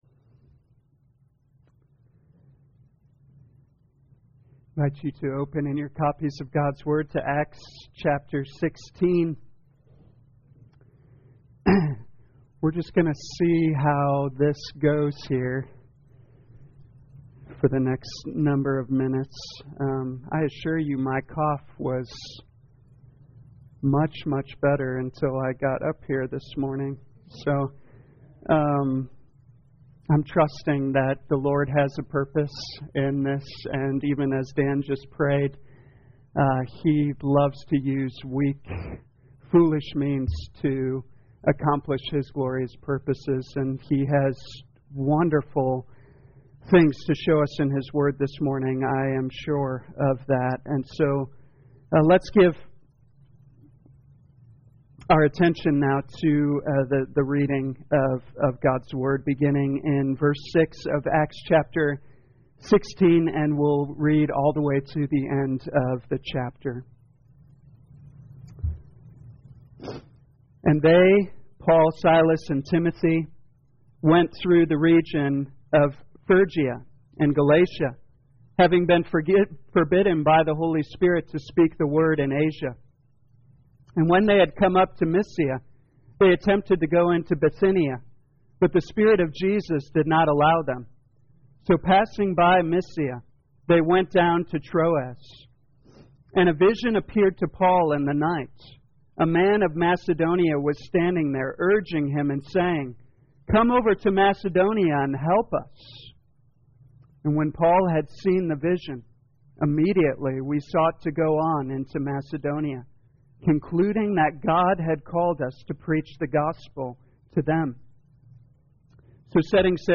2022 Acts Morning Service Download